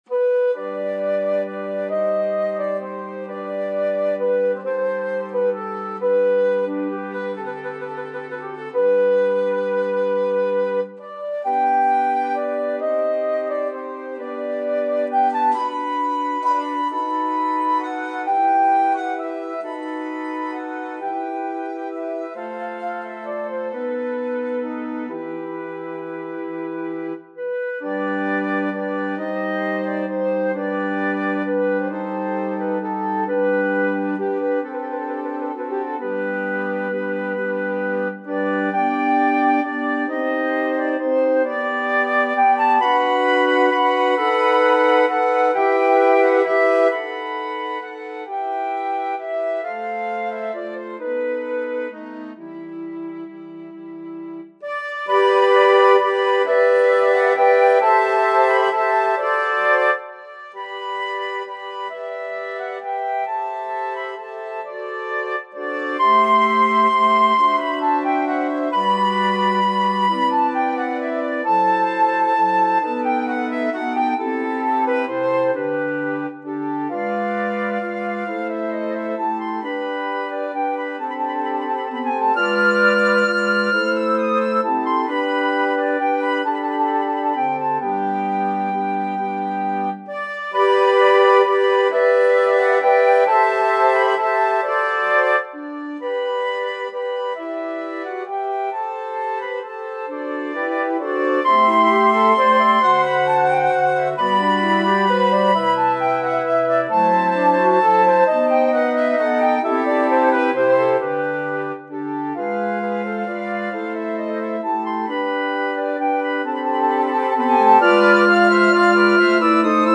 Flötenquintett
• C-Besetzung: Flöte 1/2/3, Altflöte in G, Bassflöte